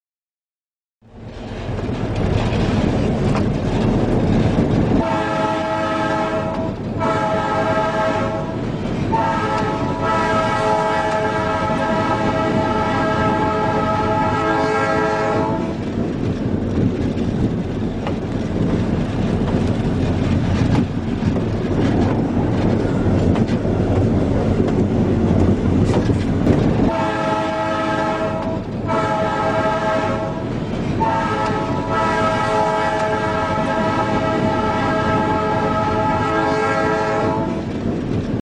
Train_Horn.mp3